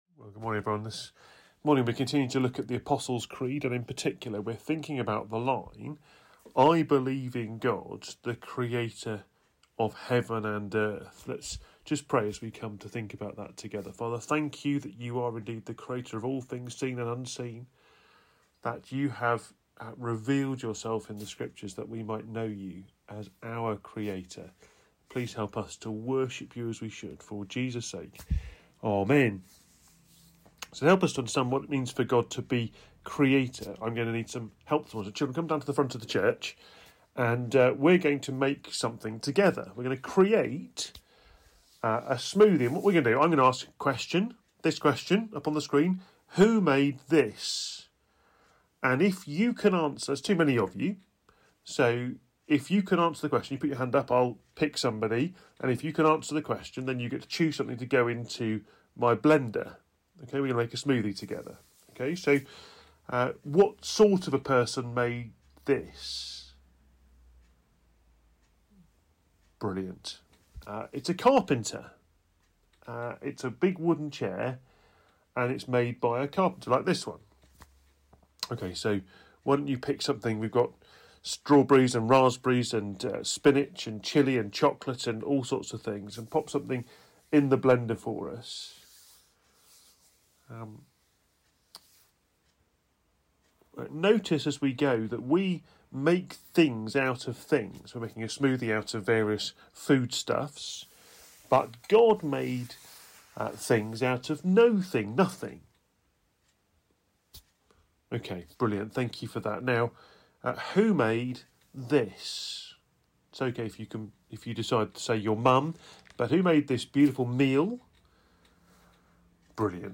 Media Library We record sermons from our Morning Prayer, Holy Communion and Evening services, which are available to stream or download below.
Genesis 1:1-5 Series: The Apostles' Creed Theme: Sermon Search